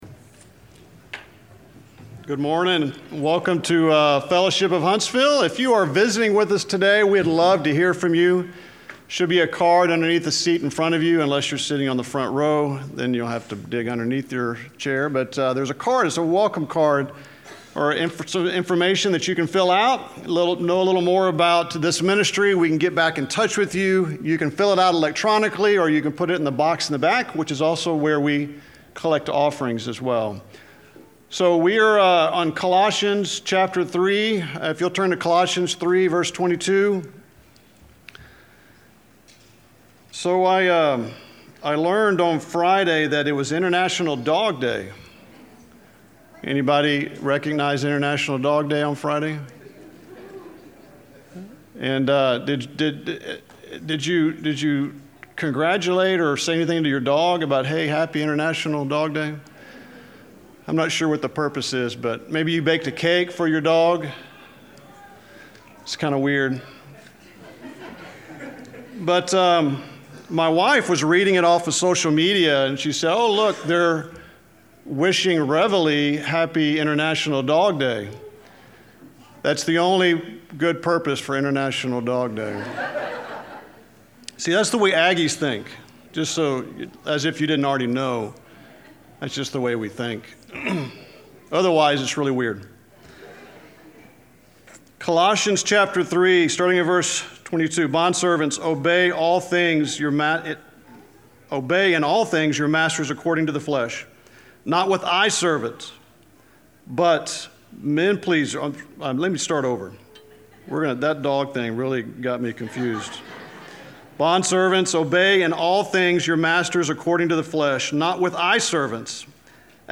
Fellowship of Huntsville Church Sermon Archive